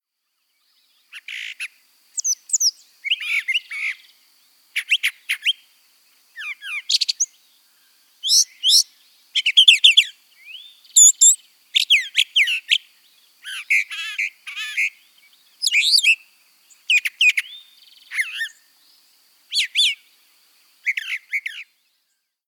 Brown Thrasher
Did you know? Brown Thrashers can have a repertoire of over 1,100 song types, including imitations of other birds. How they sound: They make a variety of sounds, including whistles, chirrups, hissing sounds, and imitations of other species.